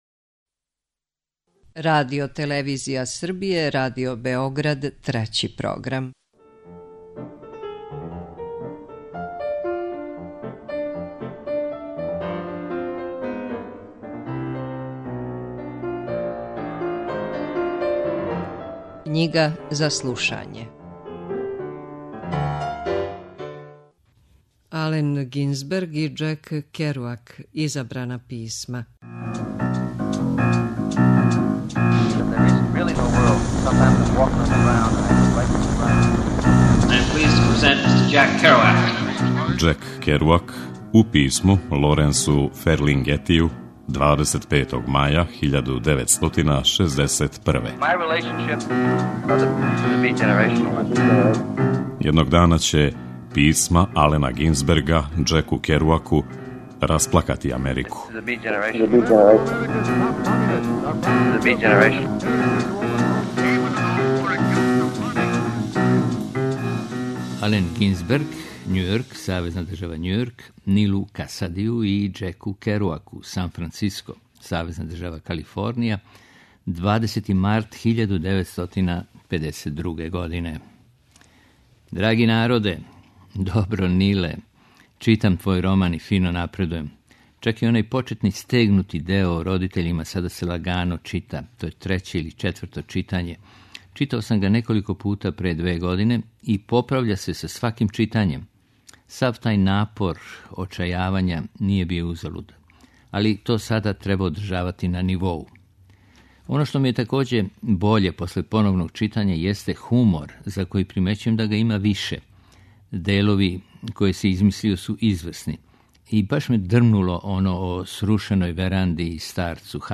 Књига за слушање